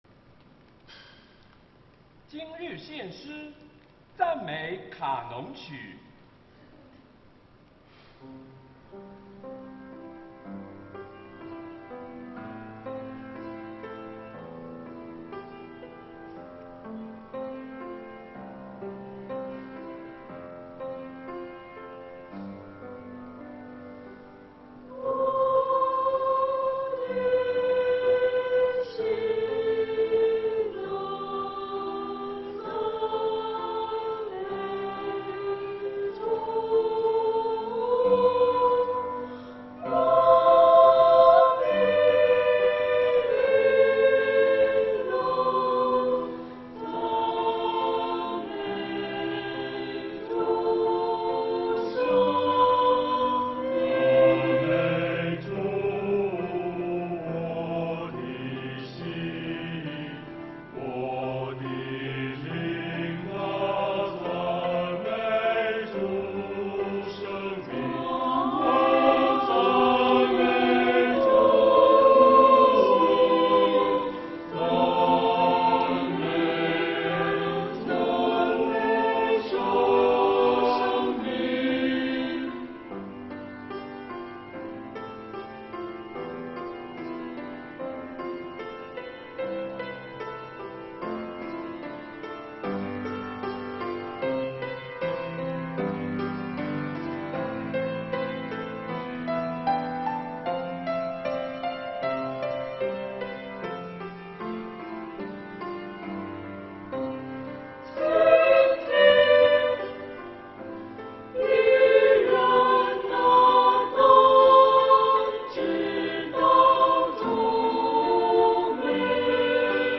[2021年7月4日主日献唱]《赞美卡农曲》 | 北京基督教会海淀堂
团契名称: 大诗班 新闻分类: 诗班献诗 音频: 下载证道音频 (如果无法下载请右键点击链接选择"另存为") 视频: 下载此视频 (如果无法下载请右键点击链接选择"另存为")